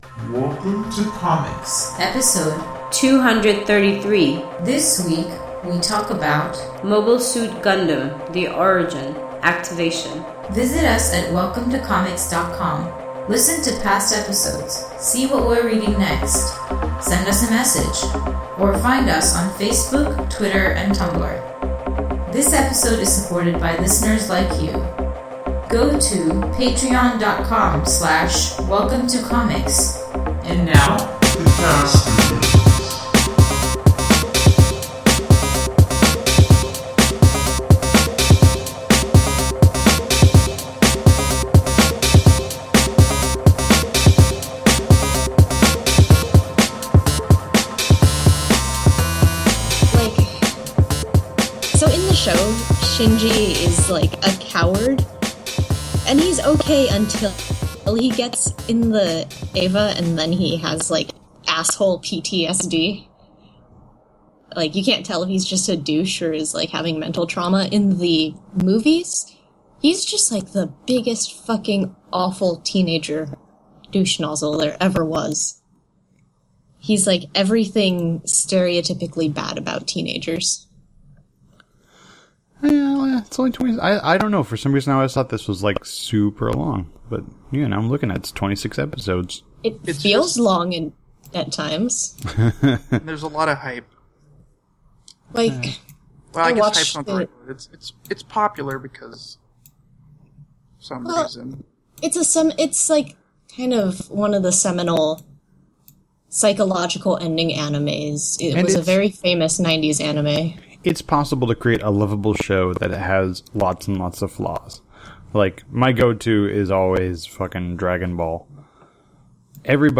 Apologies to everyone for the wonky audio quality this week.